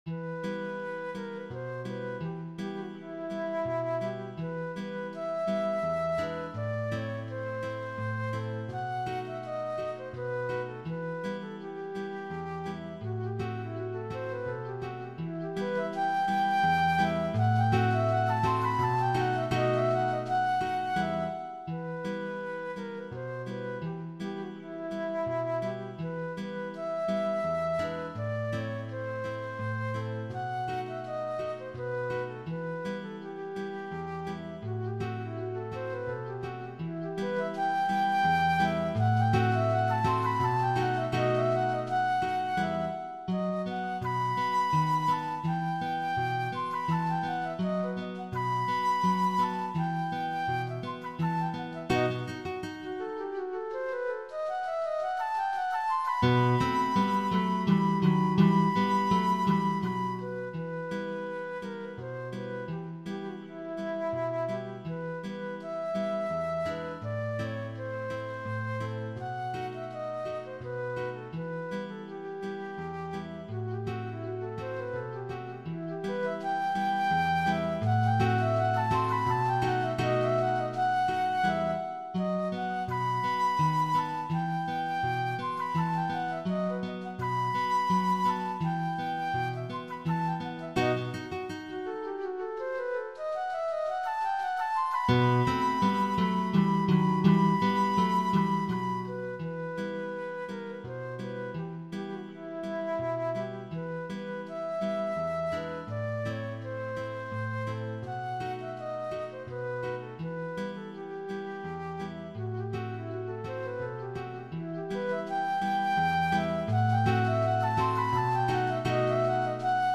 Besetzung: Melodieinstrument und Gitarre